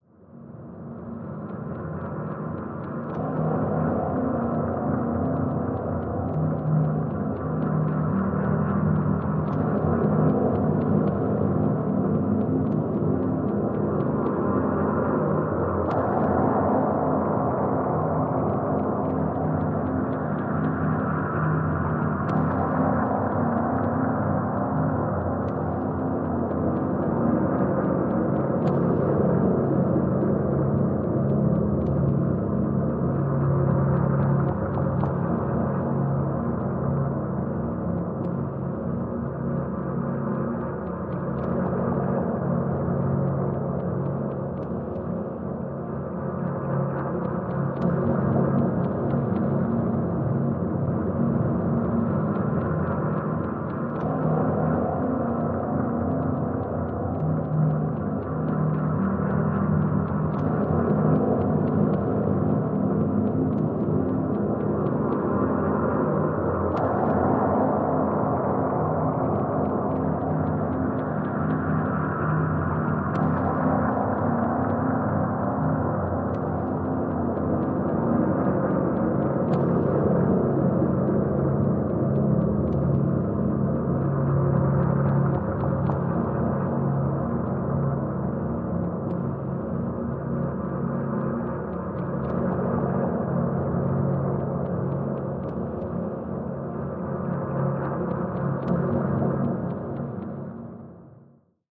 BGM
Speed 50%